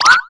jump.mp3